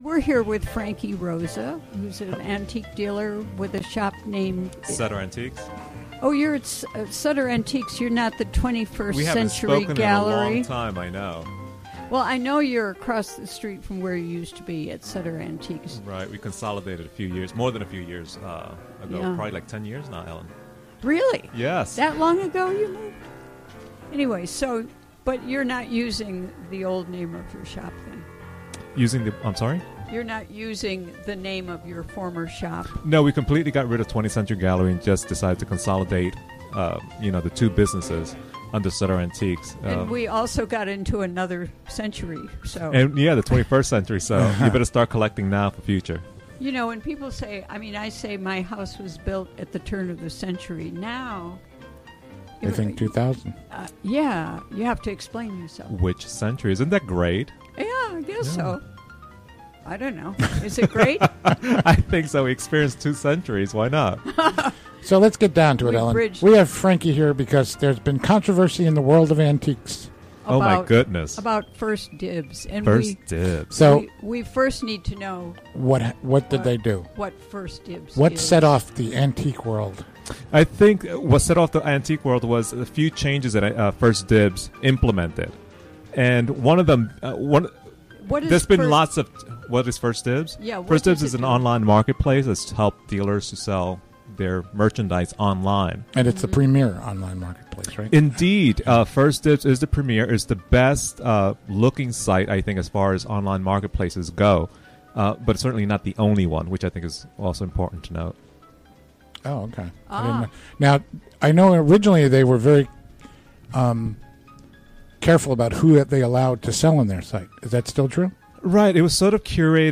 Interview from the WGXC Afternoon Show April 28.